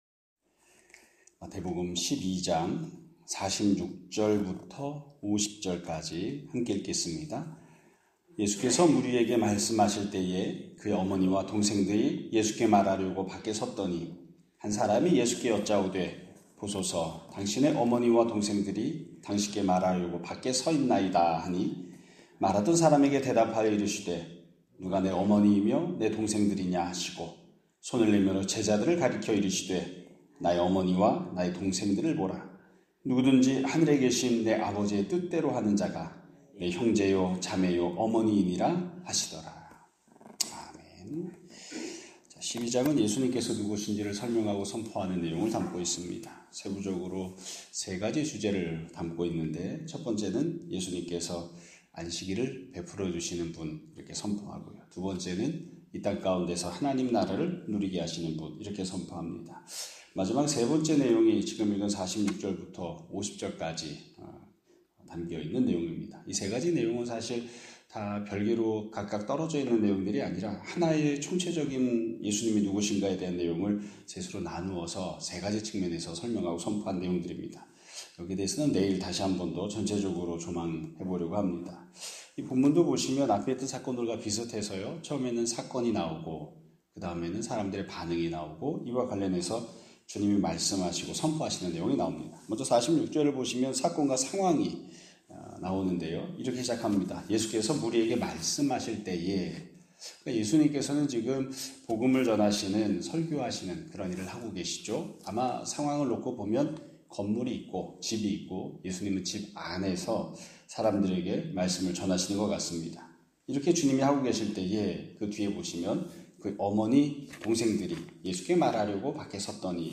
2025년 9월 22일 (월요일) <아침예배> 설교입니다.